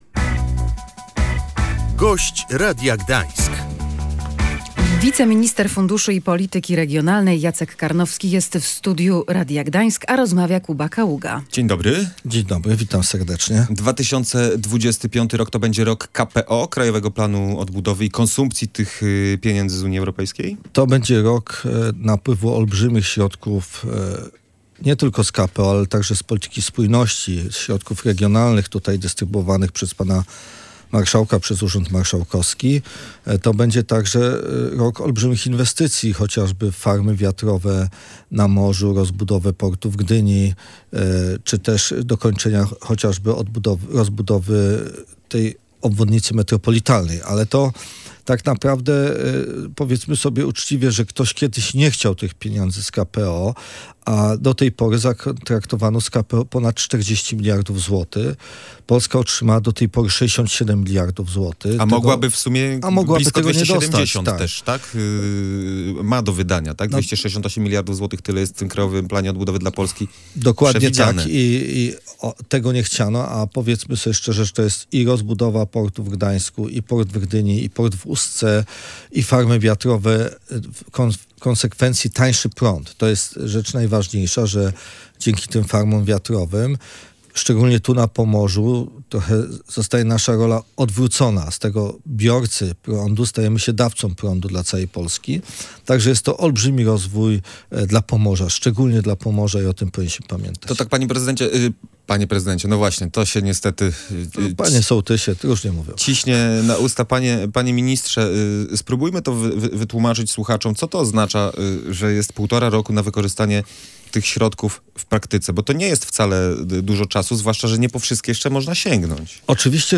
To będzie rok dużych inwestycji w Polsce dzięki unijnym pieniądzom – mówił w Radiu Gdańsk Jacek Karnowski, wiceminister funduszy i polityki regionalnej.